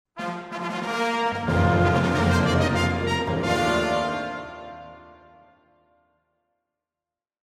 SFX – BUGLE – A
SFX-BUGLE-A.mp3